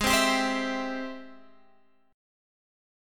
Ab+M7 chord